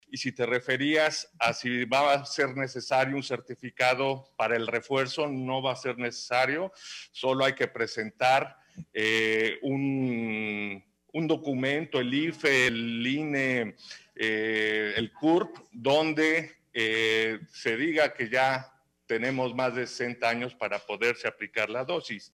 Así lo anunció el secretario de Salud estatal, quien en conferencia de prensa señaló que, espera comenzar a aplicarlas el jueves 9 de diciembre.